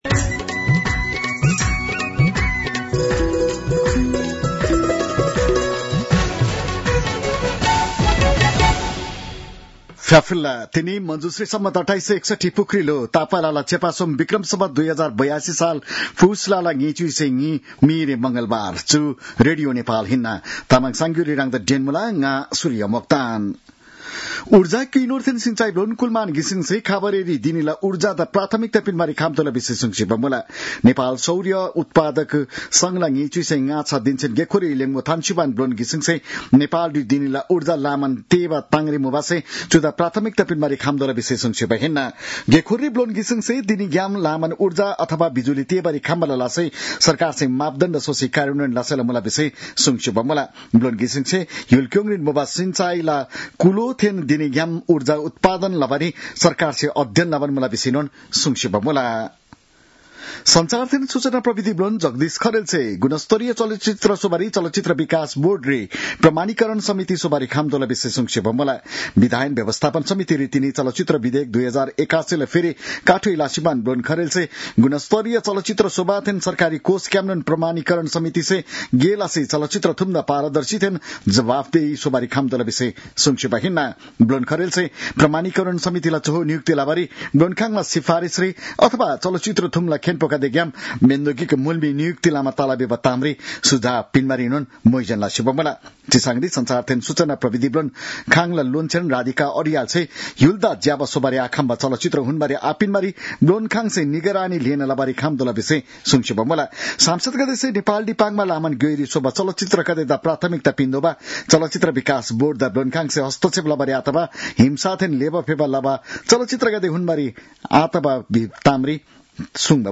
तामाङ भाषाको समाचार : २२ पुष , २०८२